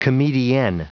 Prononciation du mot comedienne en anglais (fichier audio)
Prononciation du mot : comedienne